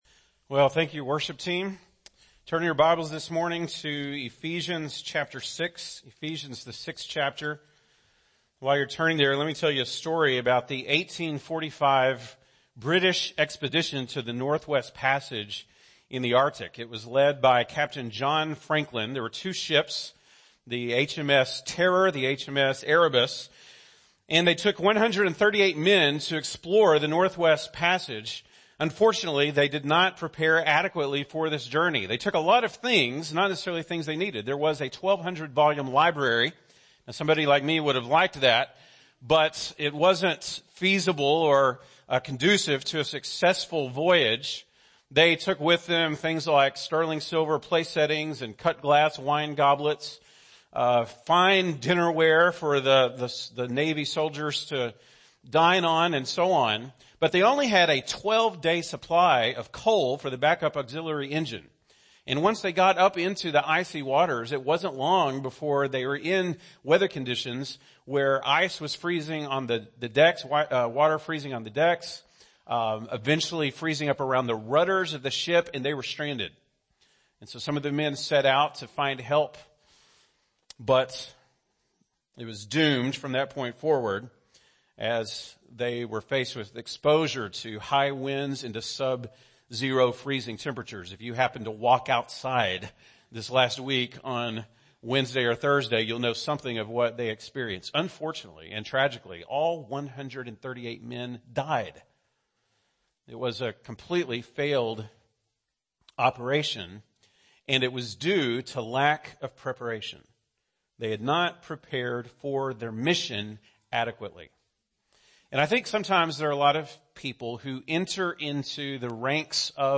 February 3, 2019 (Sunday Morning)